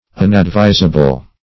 Unadvisable \Un`ad*vis"a*ble\, a.